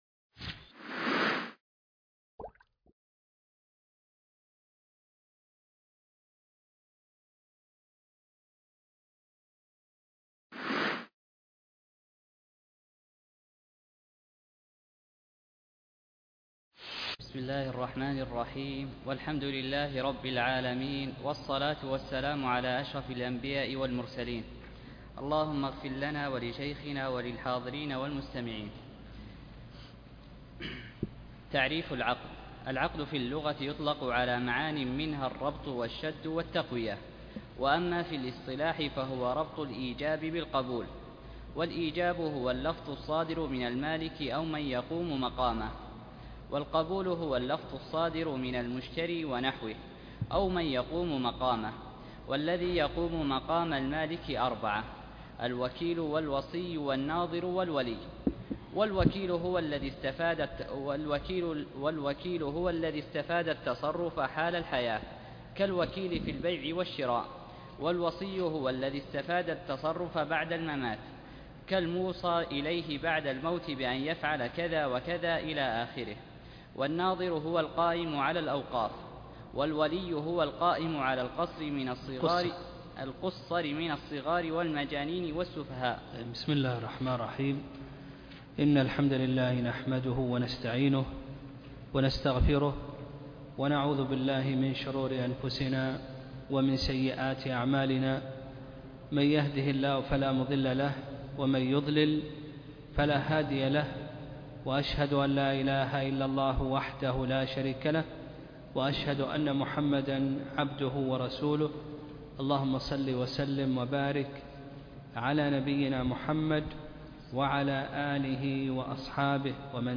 الدرس ( 1)شرح كتاب نظرية العقد